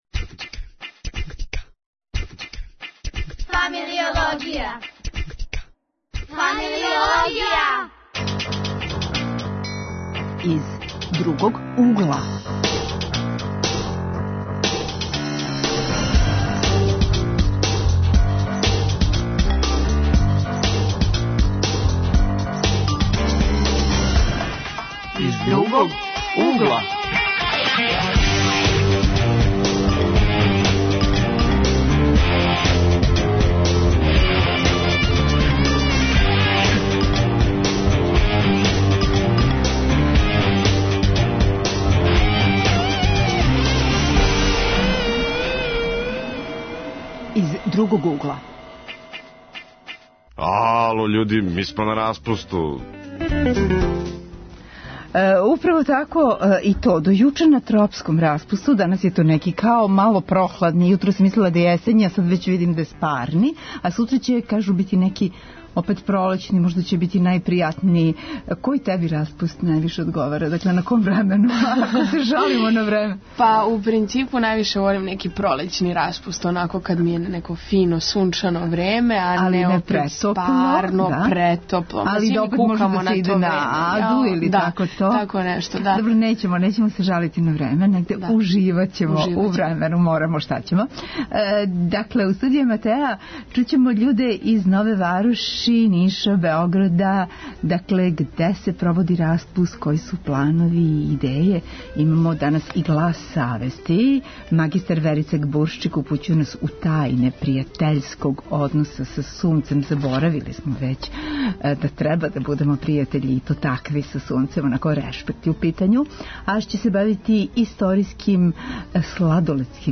Гости средњошколци и студенти. Укључујемо младе из Ниша, Нове Вароши, Суботице...